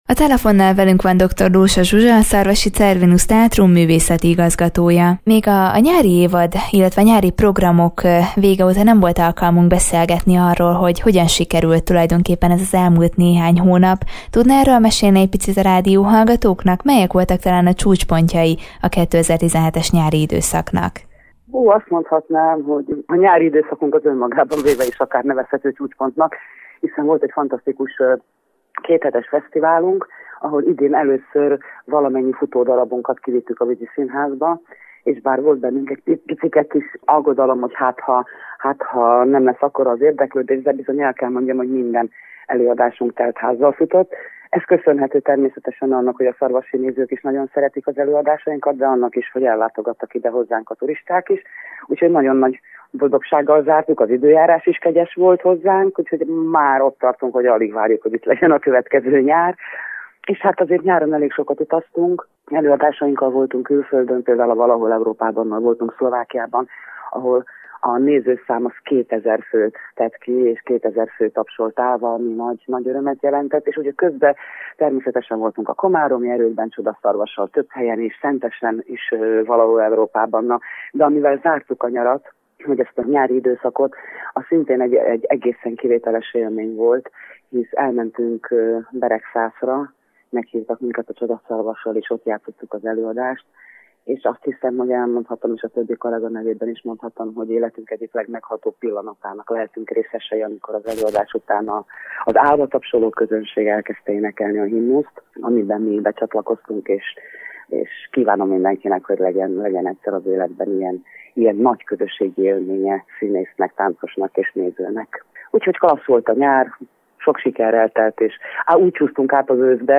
Máris elindult az új évad, melyben igazi különlegességekkel várják a nézőket. Többek között bemutatásra kerül egy korábban betiltott, komikus színdarab is Szigorúan tilos! címmel. Ezekről beszélgetett tudósítónk